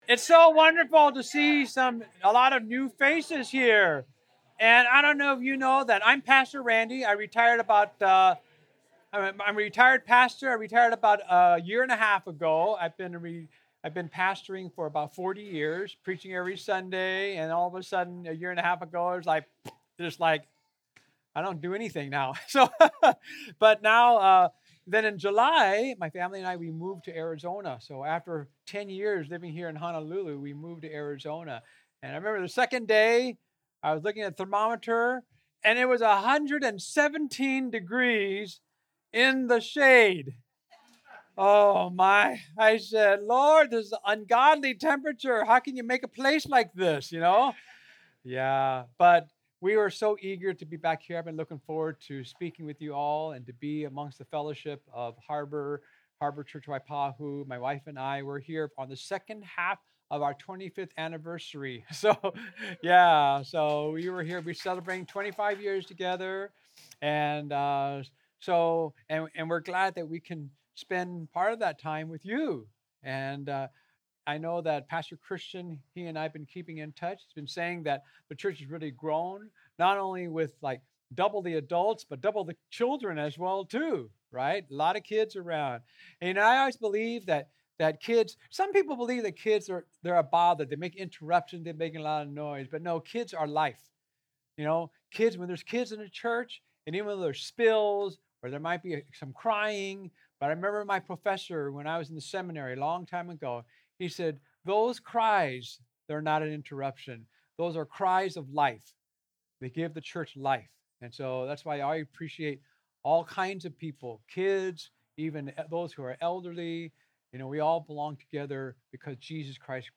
2026 Change Your Heart Preacher